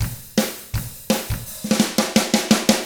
164ROCK F1-L.wav